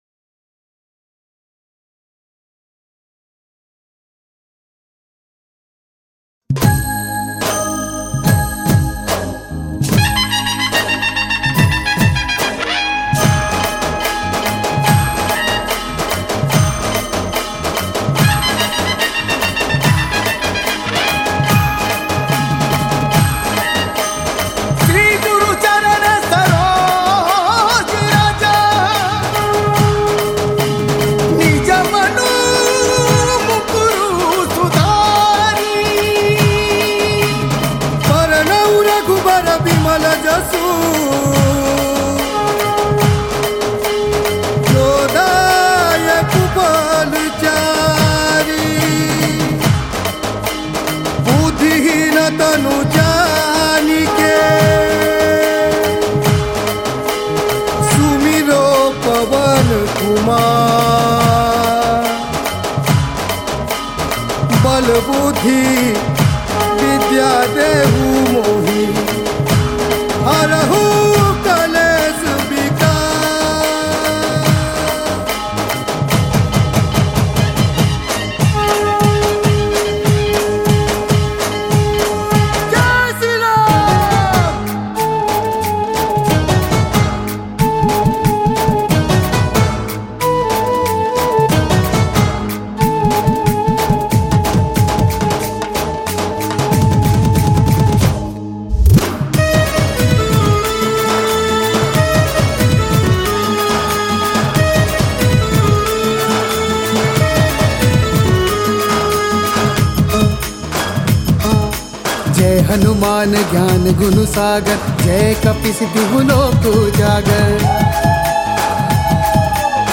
Pana Sankarati Special Bhajan Songs Download
Rythm